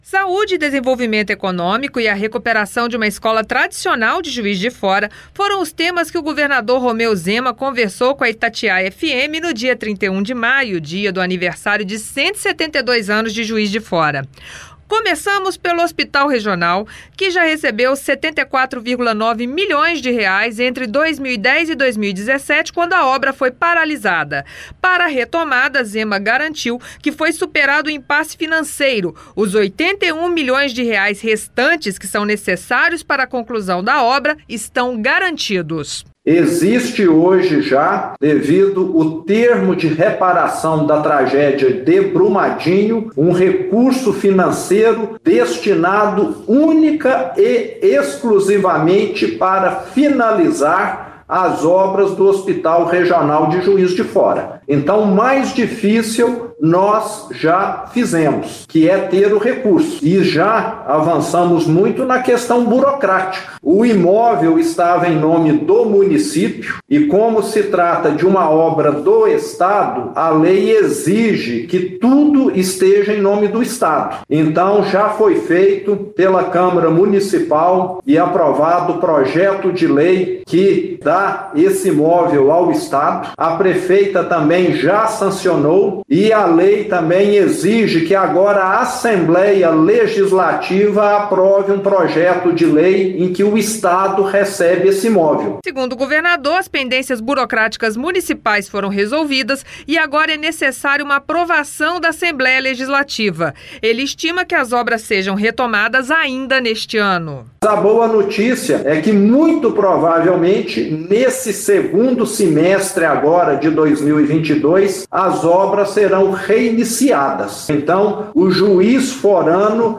Entrevista: Zema fala sobre hospital, recursos para UFJF e obra da Escola Delfim Moreira.